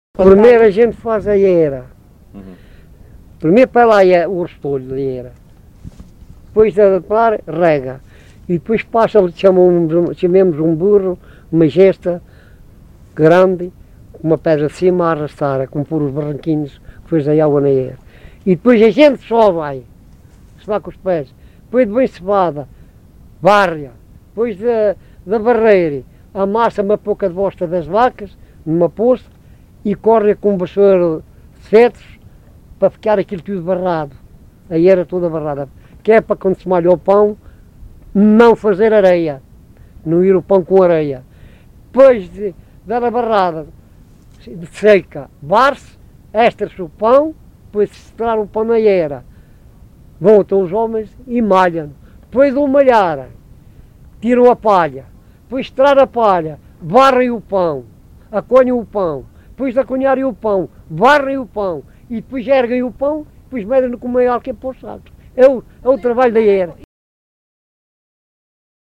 LocalidadeUnhais da Serra (Covilhã, Castelo Branco)